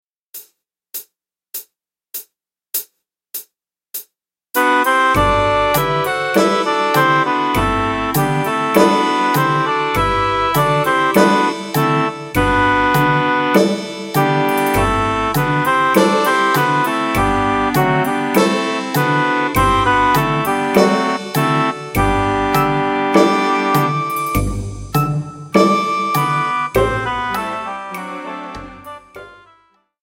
Género film / musical
• instrumentación: piano
• estilo: Musical o Show